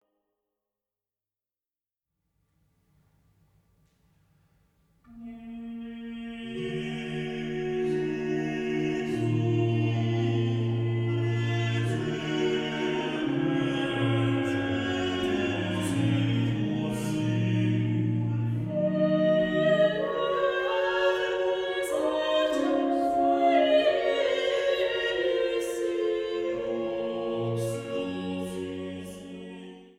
6- bis 7-stimmige Motetten der Cantiones Sacrae I